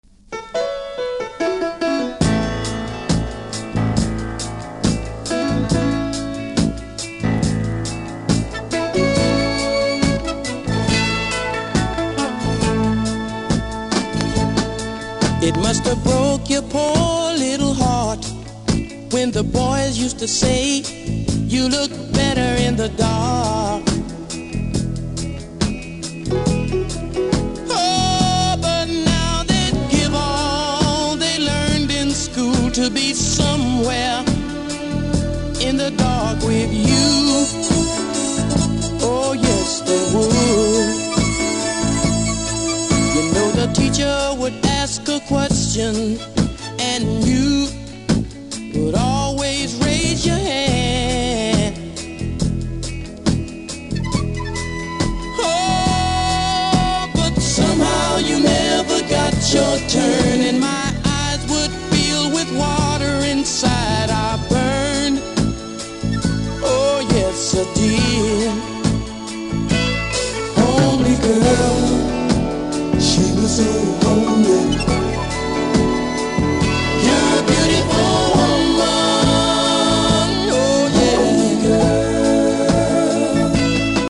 70'S MALE GROUP